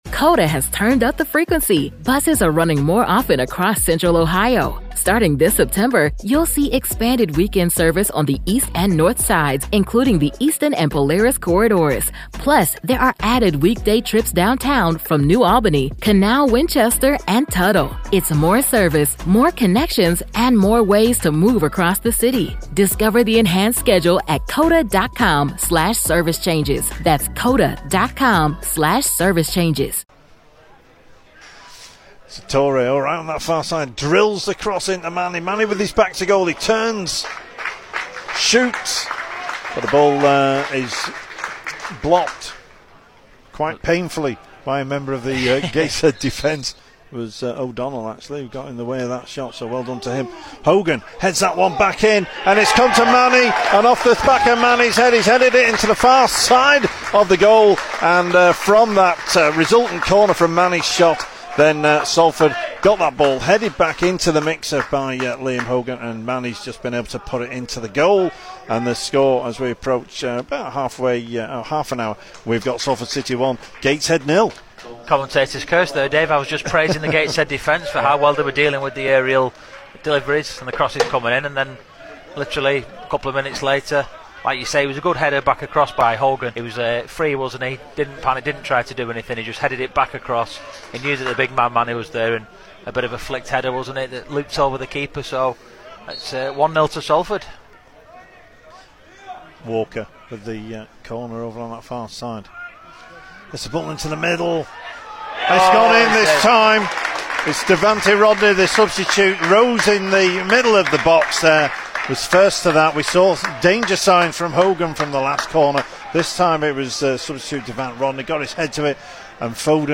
Highlights